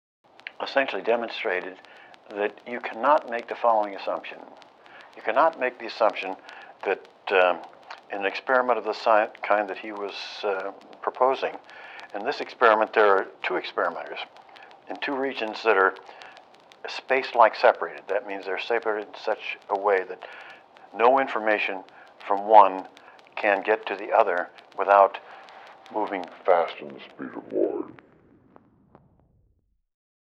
SOS Tutorial 135 - Lo-Fi-Effekte mit iZotopes Vinyl (Freeware)
Vinyl ist ein kostenloses Plug-in von iZotope, mit dem sich die Klangeigenschaften analoger Schallplatten und Plattenspieler simulieren lassen.